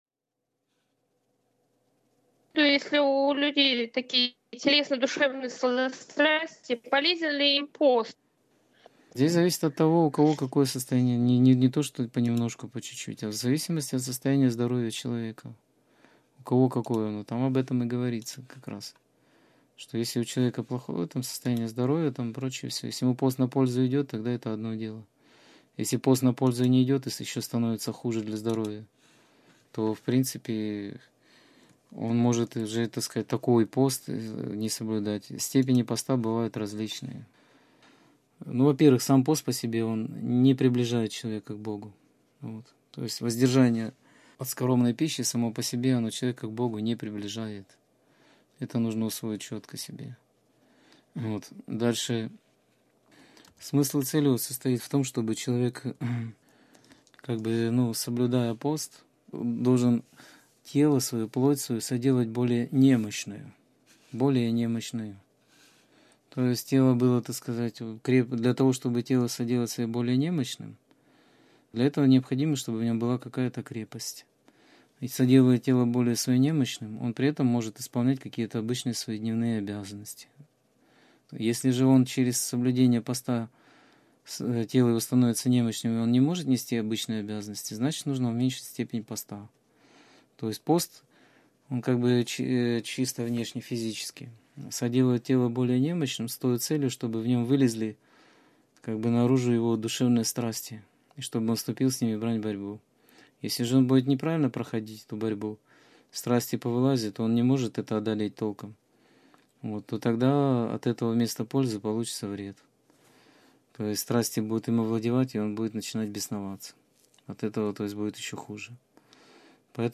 Скайп-беседа 1.03.2014 — ХРИСТИАНСКАЯ ЦЕРКОВЬ
Проповедь о любви прелестных лжестарцев.